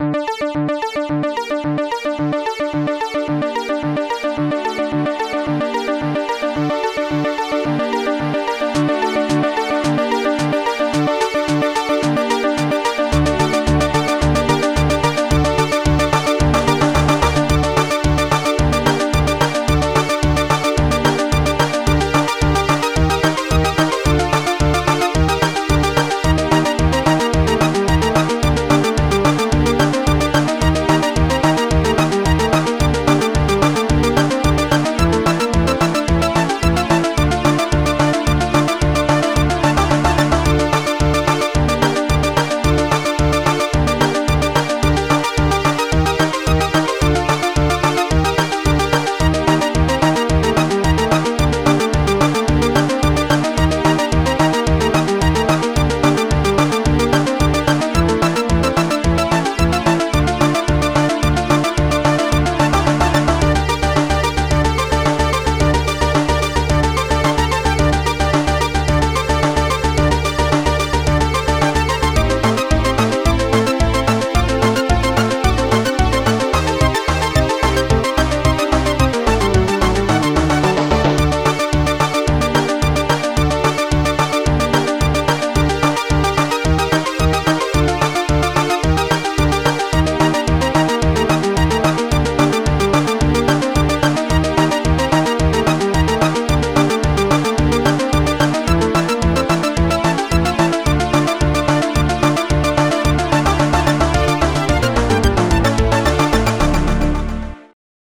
s3m (Scream Tracker 3)
a remix of the theme tune